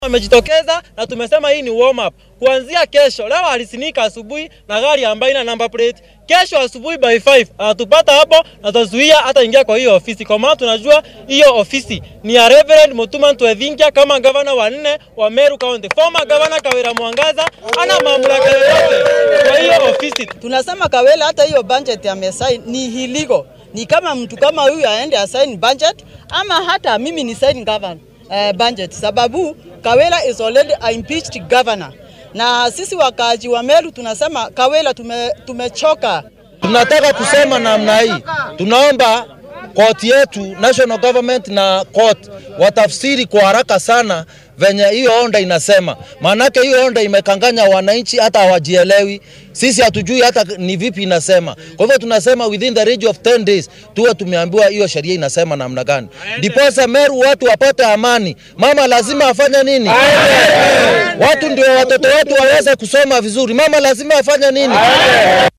Buuq iyo sawaxan ayaa ka laga soo tabiyay dhismaha xafiiska barasaabka ismaamulka Meru Kawiga Mwangaza. Dadka deegaanka ayaa sameeyay mudaaharaad, iyaga oo dalbanaya in Kawira Mwangaza ay xafiiska baneyso.